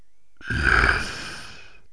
bull_select3.wav